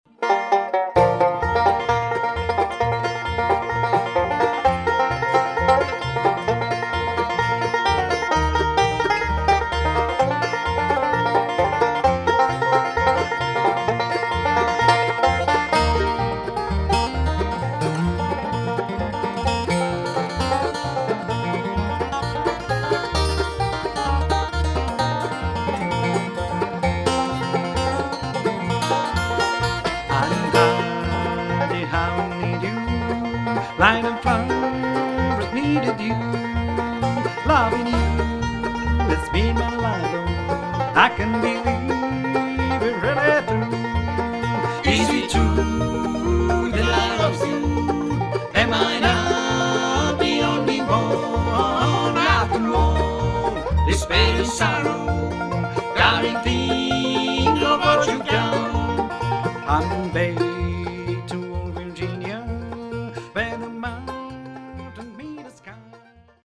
mandolína, gitara, lead, tenor vokál
banjo, tenor vokál
bass, bariton vokál